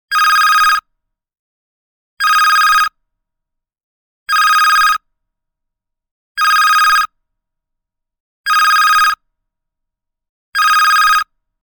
Mobile Phone Ringtone
Classic ringtone sound effect for mobile or digital phone.
Genres: Sound Effects
Mobile-phone-ringtone.mp3